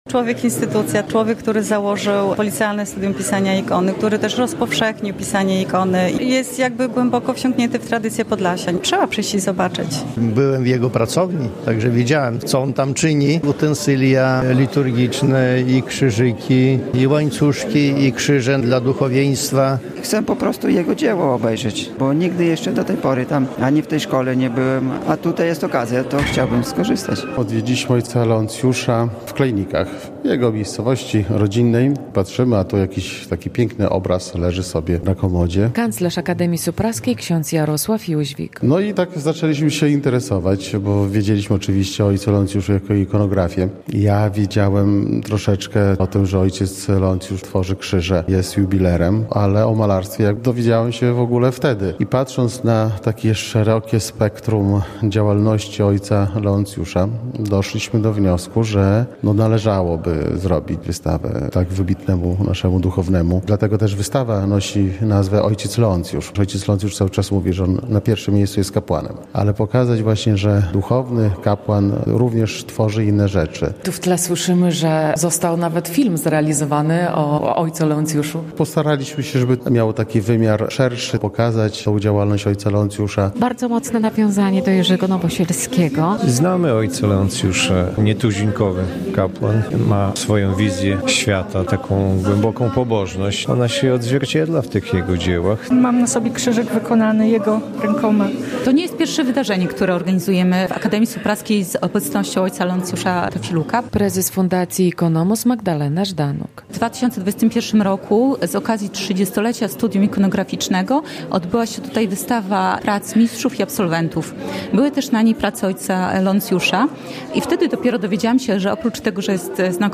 relacja
To niesamowita wystawa - podkreślają przybyli na wernisaż goście.